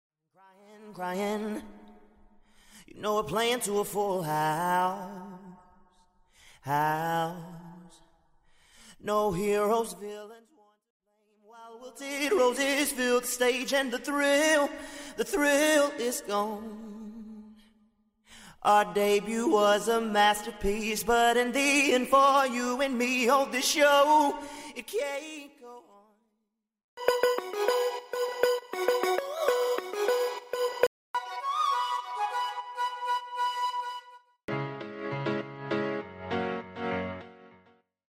(Studio Drums Stem)
(Studio Organic Strings Stem)
(Studio Piano Keys Stem)
(Studio Synths Stem)